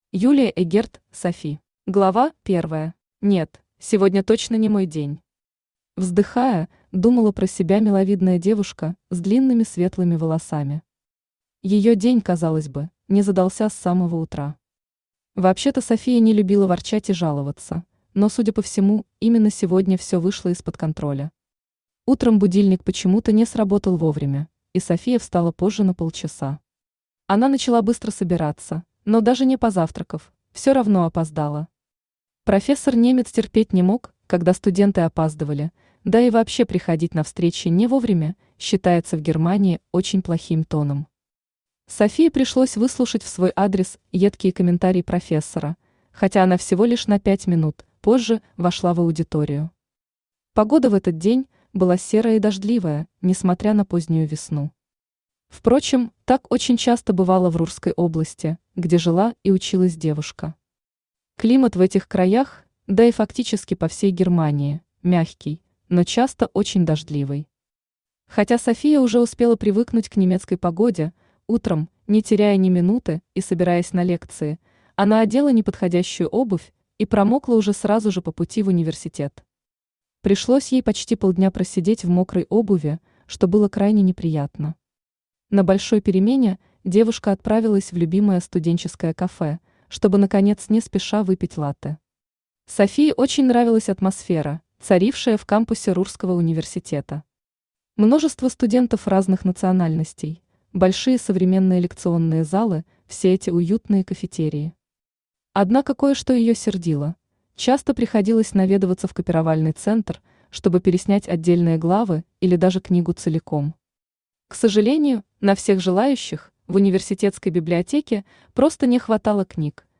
Аудиокнига Софи | Библиотека аудиокниг
Aудиокнига Софи Автор Юлия Эгерт Читает аудиокнигу Авточтец ЛитРес.